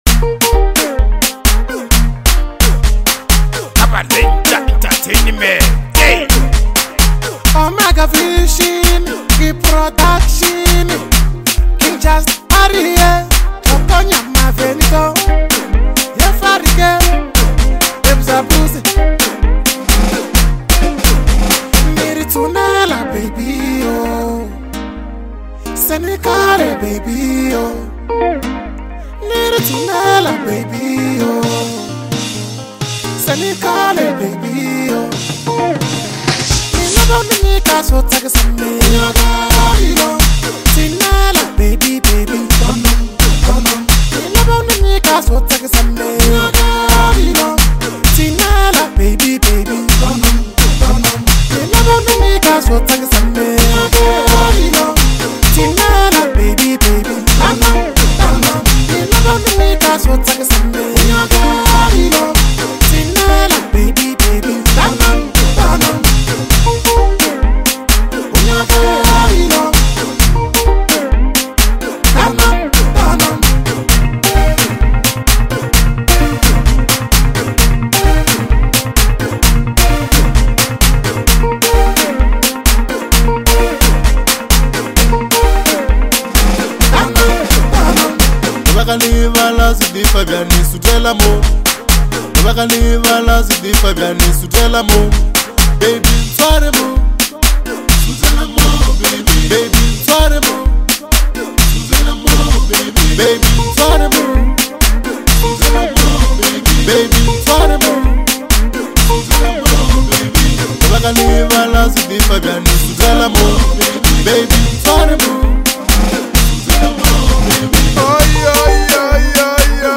is a fiery and captivating track